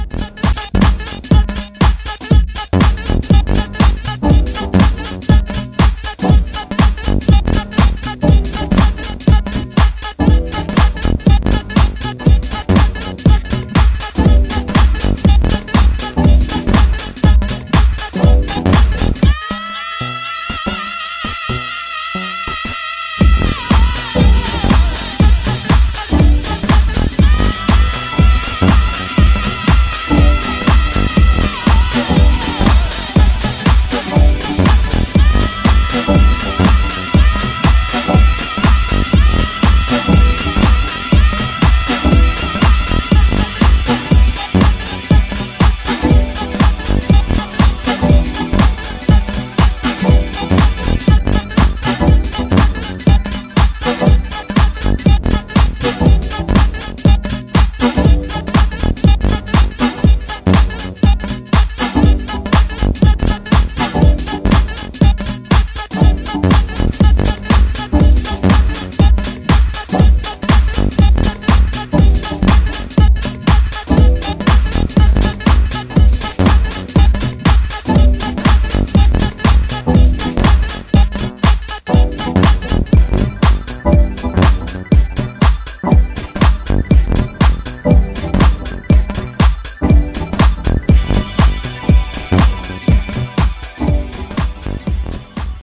バウンシーなビートに女性Vo＆シャウトを配した